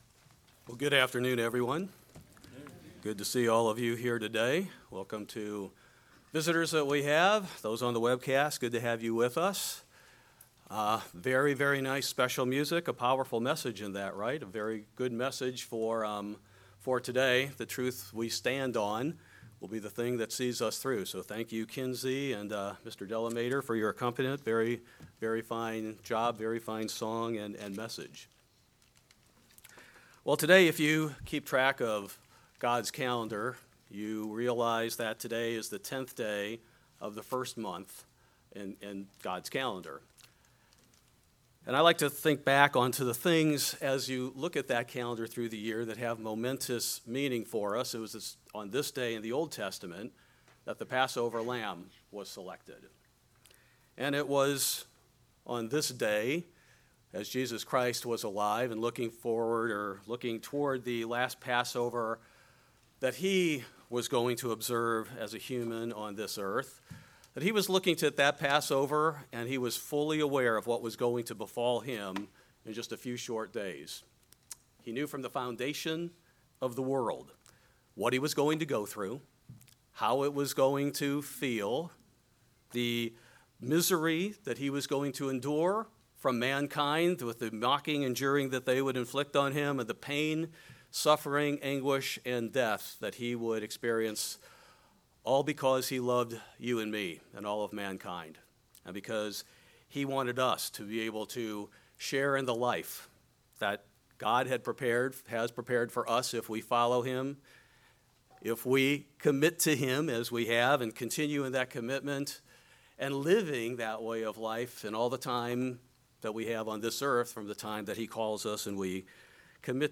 Sermon from the Stake | United Church of God